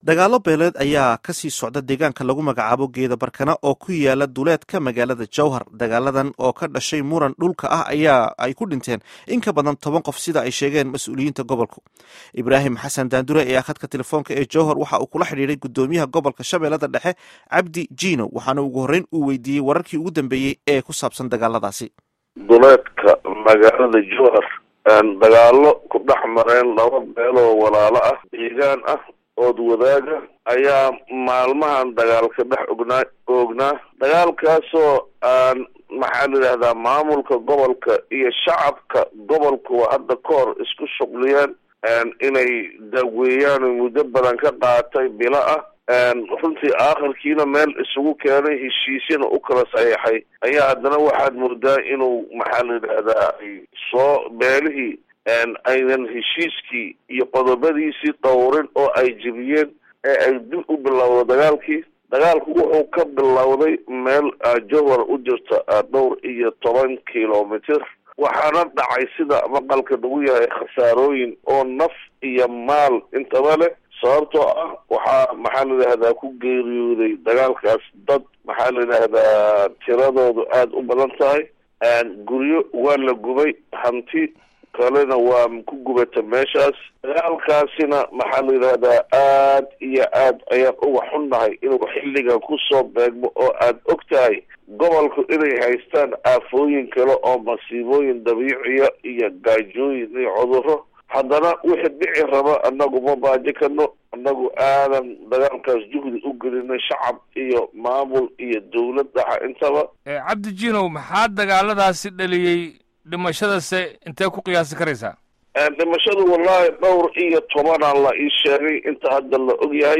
Dhageyso Wareysiga Gudoomiye Cabdi Jiinow